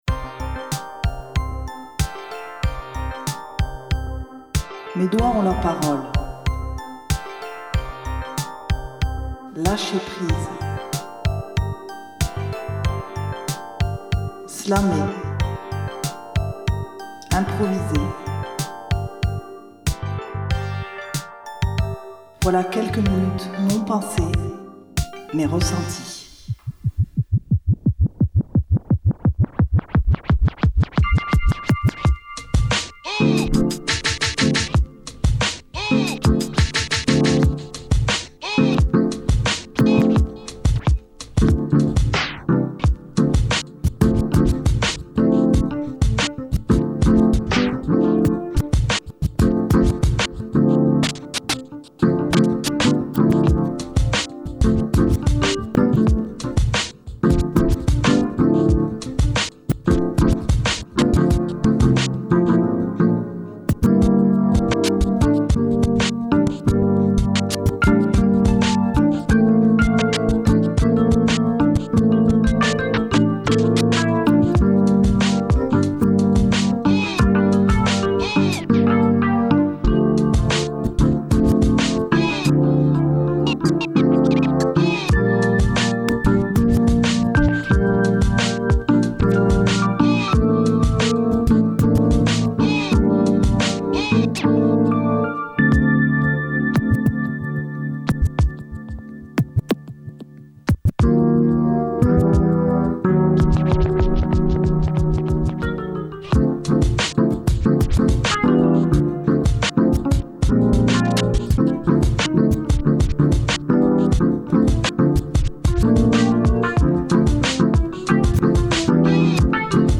improvisé
au clavier
au scratch